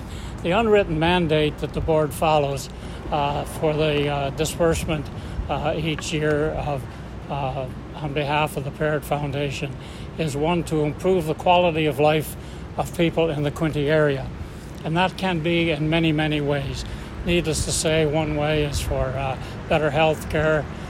At a small media event in front of the current Prince Edward County Memorial Hospital, where the foundation has a fundraising thermometer tracking progress, it was announced that the John M. and Bernice Parrott foundation donated $750,000 to the cause.
Parrott Foundation President Lyle Vanclief was on hand to speak about the donation.